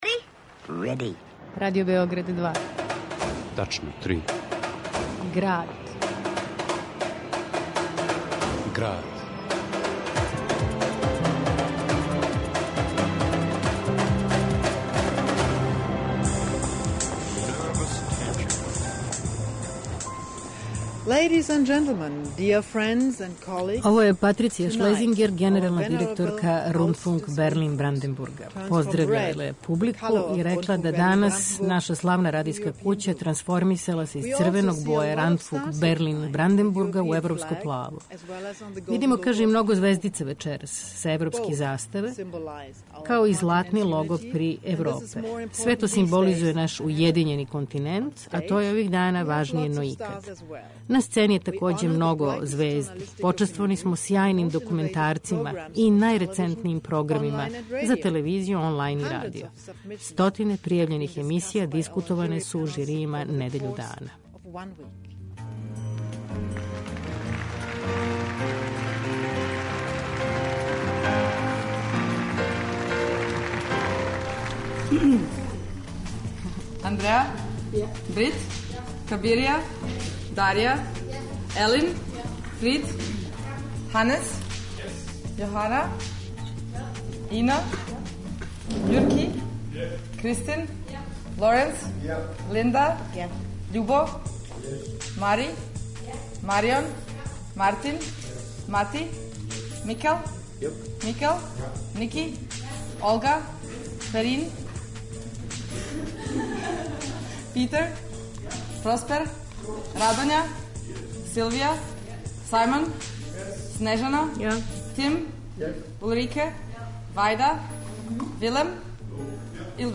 У Граду - инсерти из награђених радијских емисија те утисци и коментари неких од учесника овогодишњег фестивала...